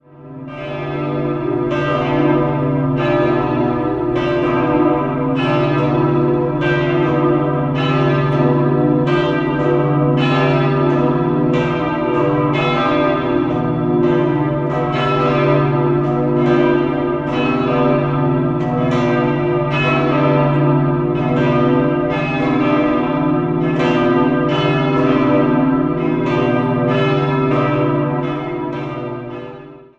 3-stimmiges TeDeum-Geläute: h°'-d'-e' Die kleine Glocke wurde 1532 von Hinrik van Kampen, die mittlere 1913 von der Glockengießerei M & O Ohlsson in Lübeck und die große 1929 von Lauchhammer gegossen.